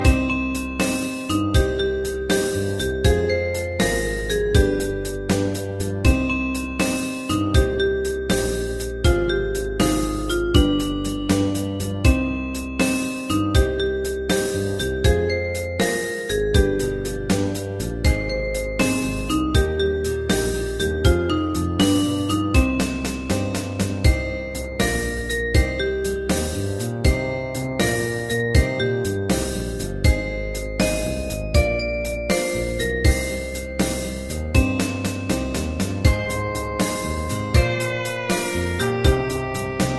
Unison musical score and practice for data.